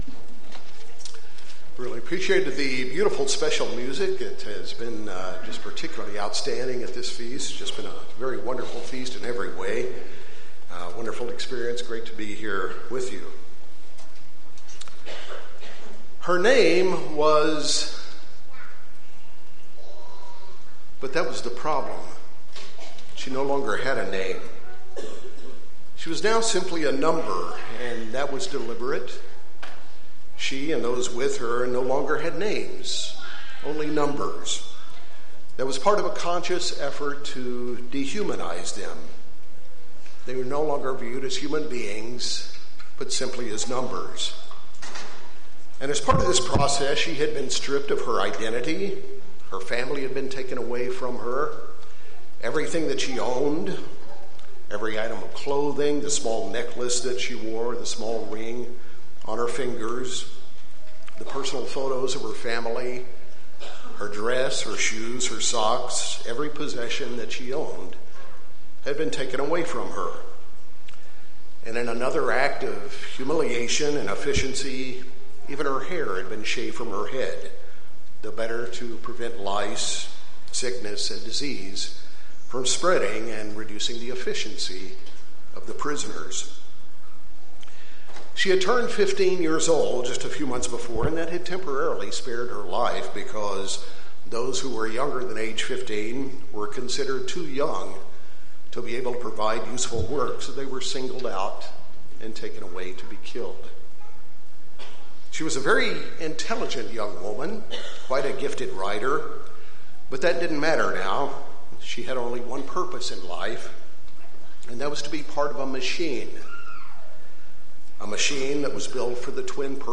This sermon was given at the Anchorage, Alaska 2018 Feast site.